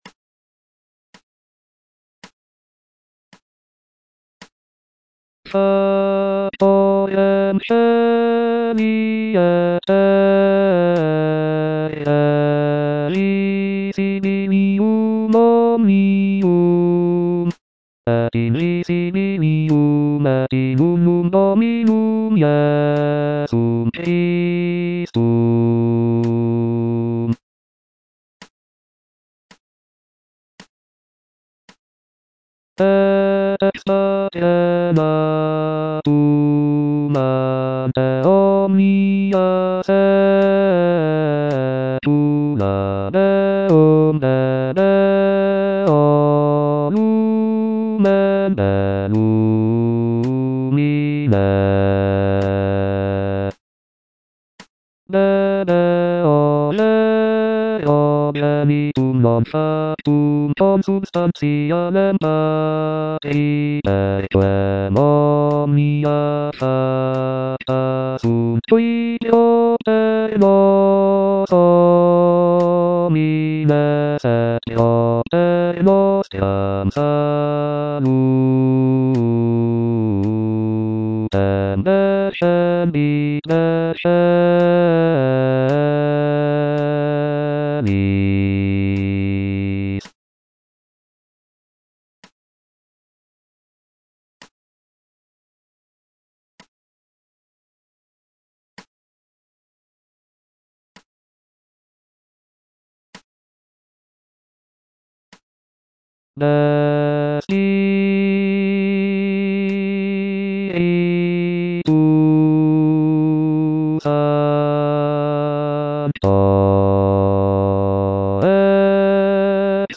T.L. DE VICTORIA - MISSA "O MAGNUM MYSTERIUM" Credo - Bass (Voice with metronome) Ads stop: auto-stop
It is renowned for its rich harmonies, expressive melodies, and intricate counterpoint.